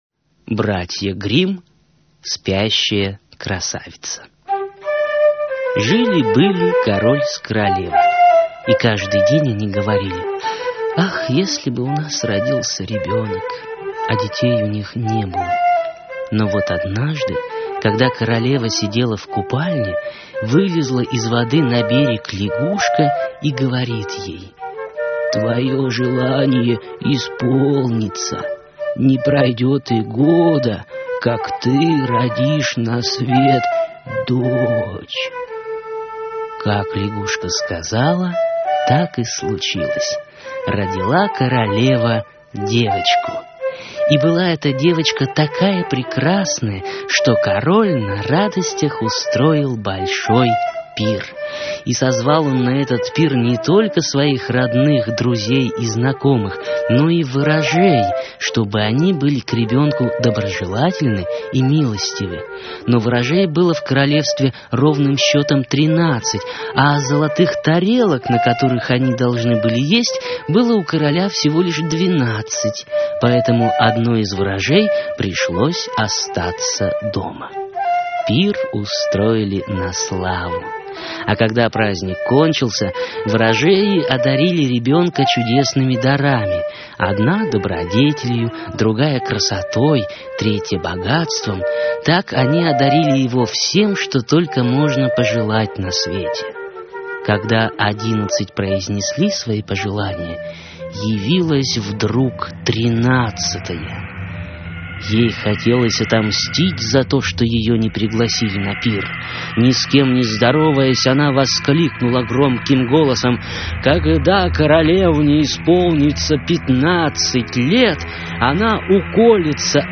АУДИОСКАЗКИ